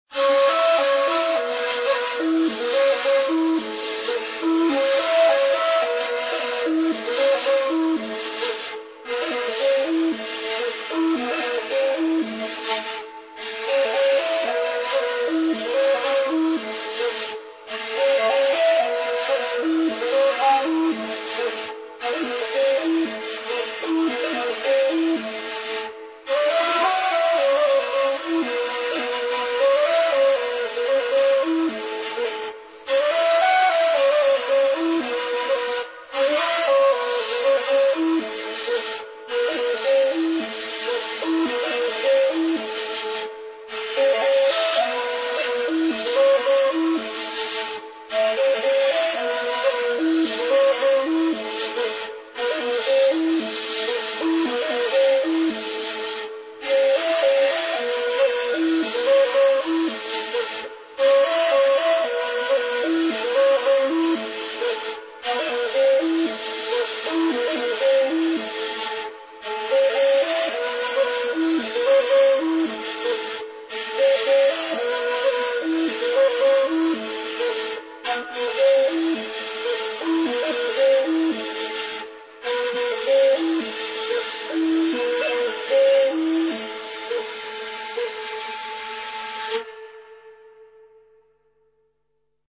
• Главная » Файлы » Музыкальные произведения » Кюи
Сыбызгы